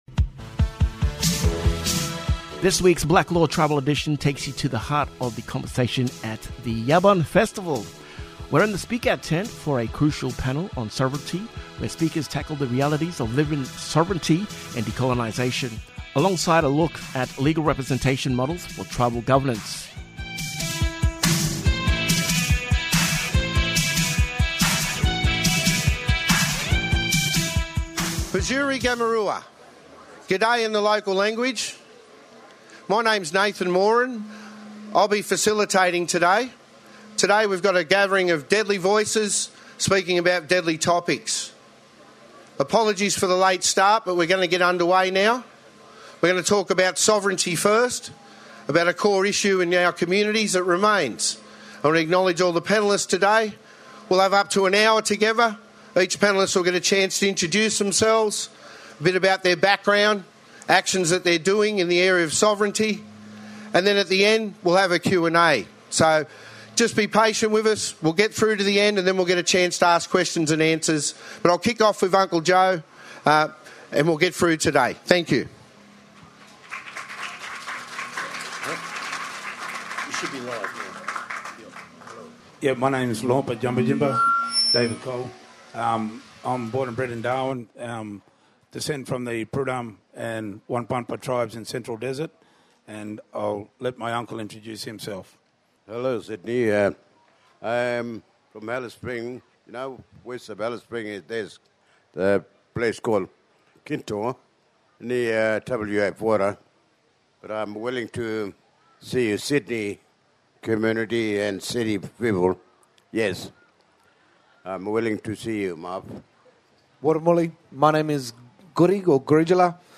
Blak Law Tribal Edition takes you to the heart of the conversation at the Yabun Festival. We’re in the Speak Out tent for a crucial panel on sovereignty, where speakers tackle the realities of living sovereignty and decolonisation, alongside a look at legal representation models for tribal governance.”